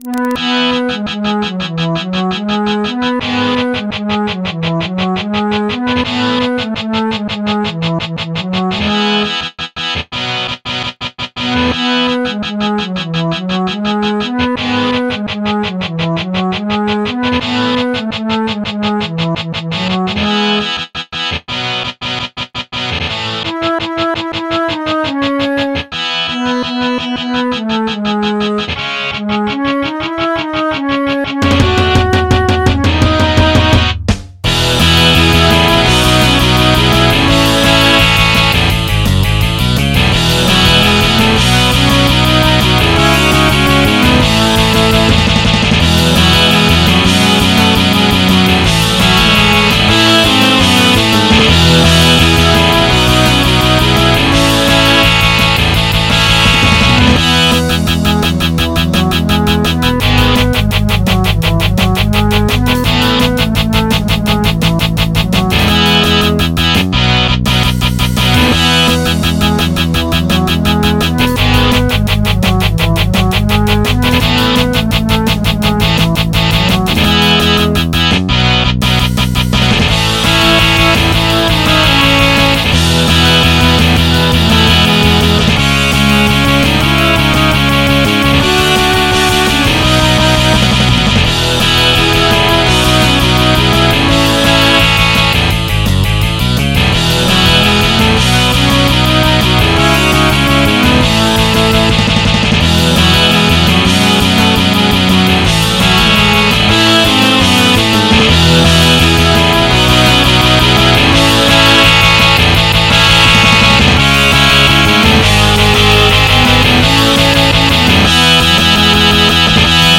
MIDI 94.1 KB MP3 (Converted) 2.86 MB MIDI-XML Sheet Music